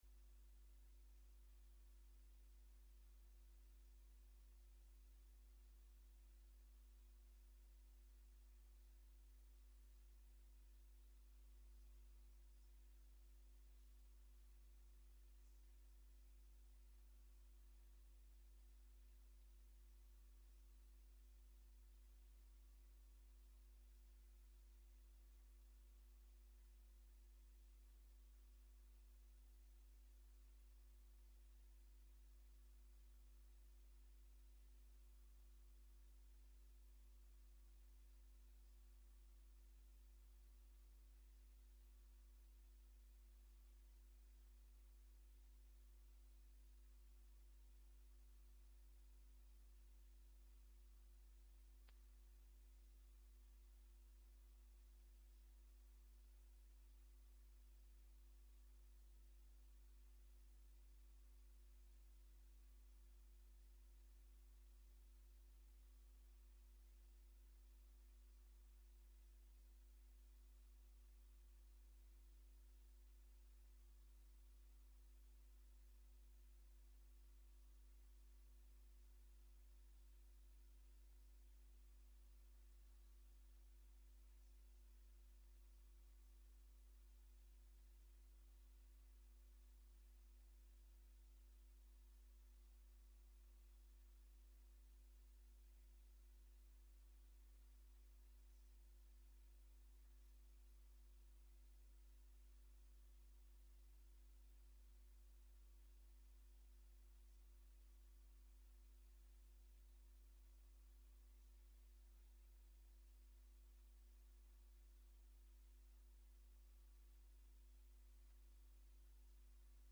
A message from the series "Sunday Morning."